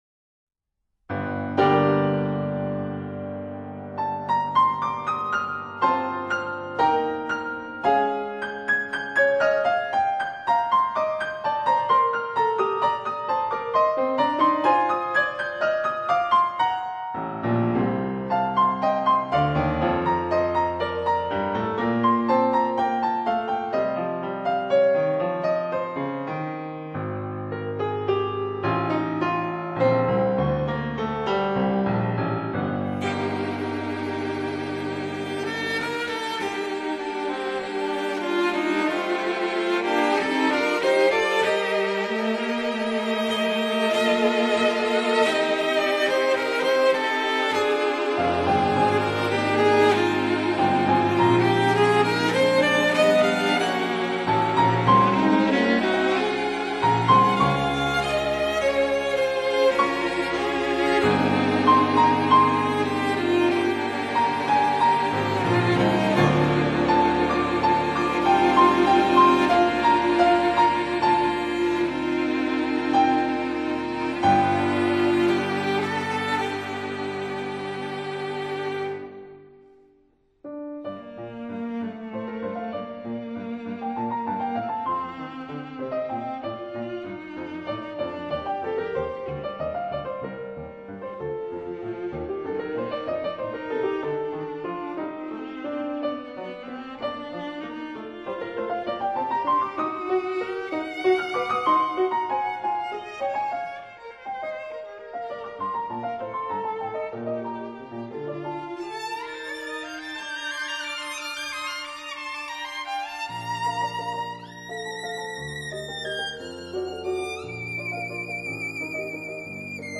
分辑信息：CD20-22 室内乐